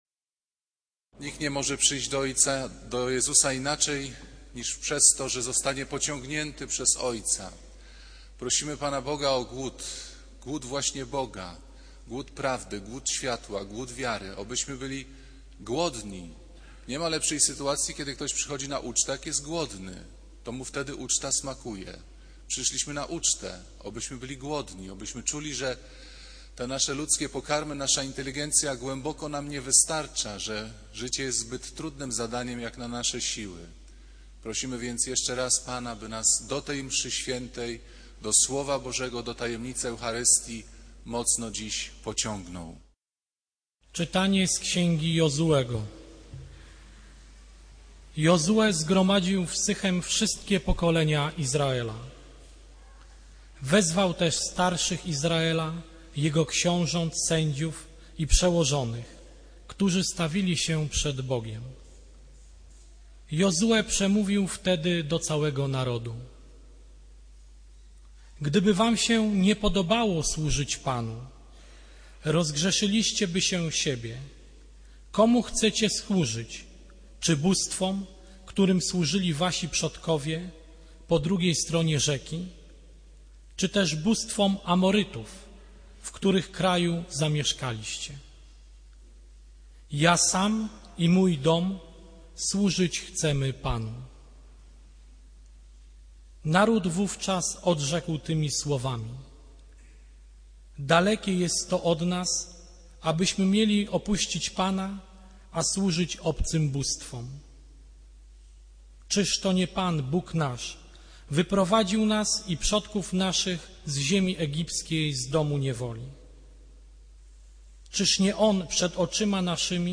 Kazanie z 23 sierpnia 2009r.